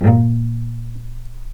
healing-soundscapes/Sound Banks/HSS_OP_Pack/Strings/cello/pizz/vc_pz-A#2-pp.AIF at 48f255e0b41e8171d9280be2389d1ef0a439d660